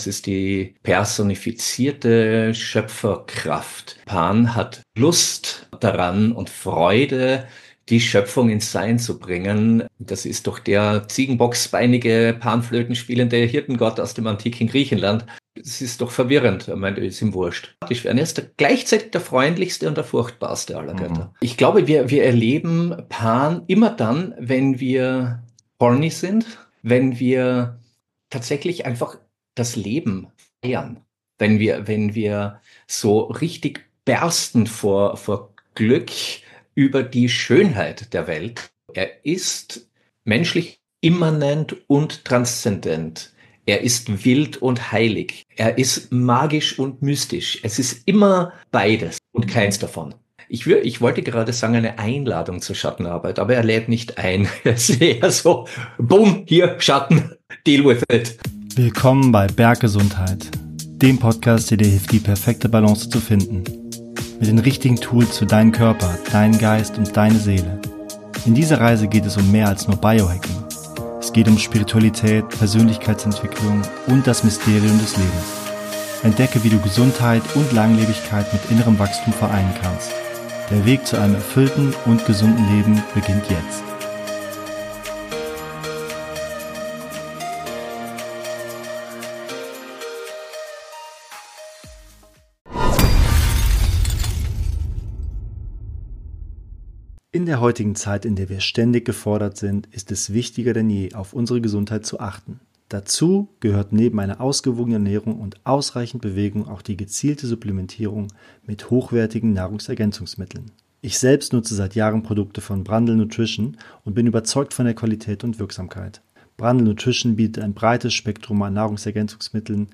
Ein Gespräch über Mythos, Archetypen und die Kraft, die Welt lebendiger zu machen.